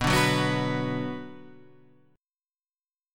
B Minor